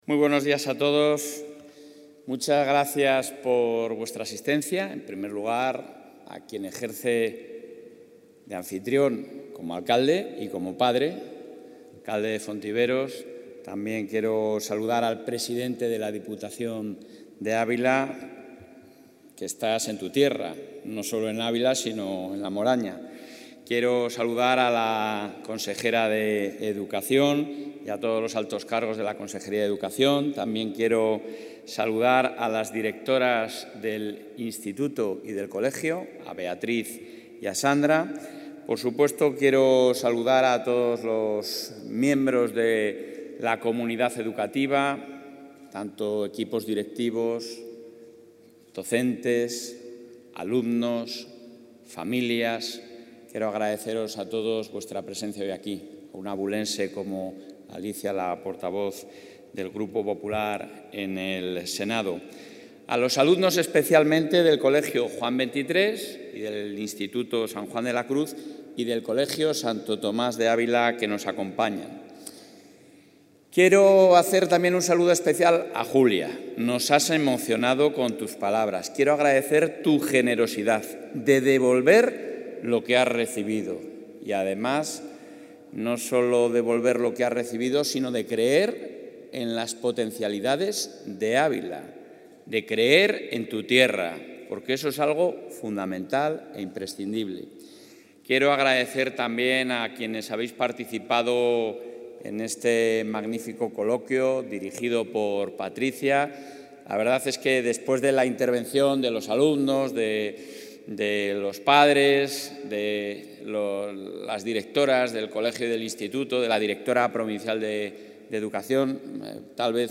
Intervención del presidente de la Junta.